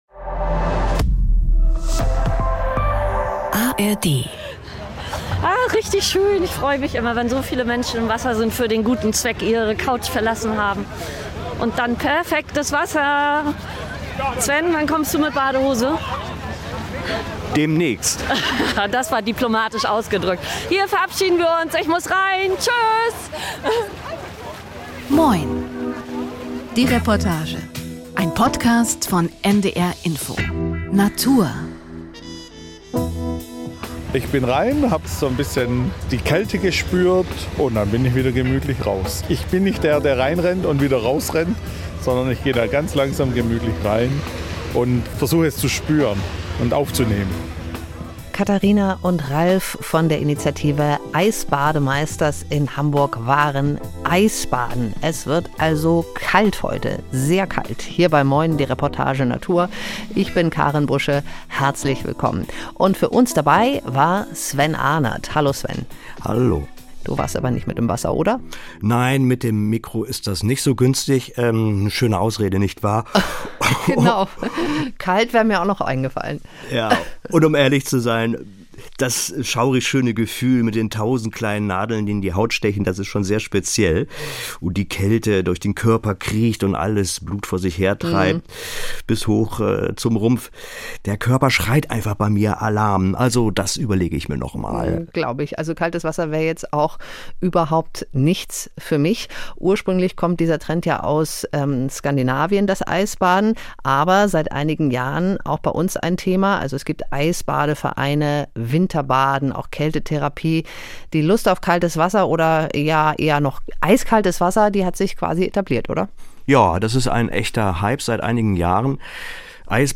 Die Reportage podcast